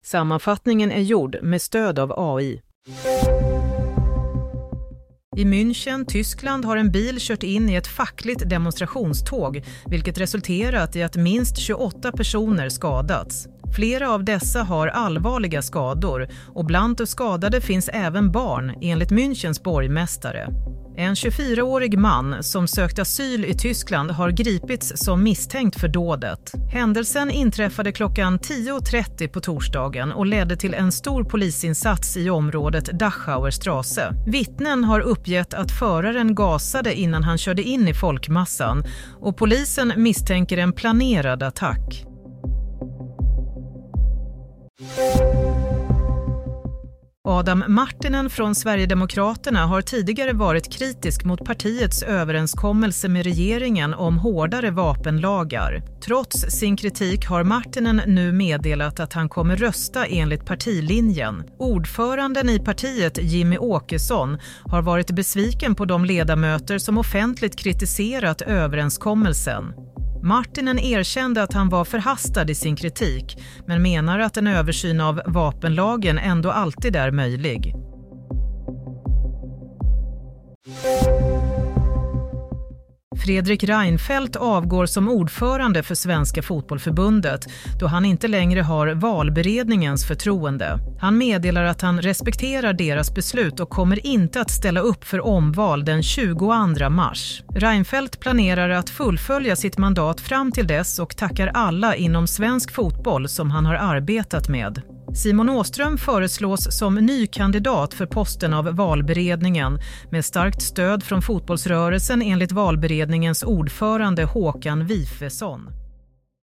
Nyhetssammanfattning – 13 februari 16.00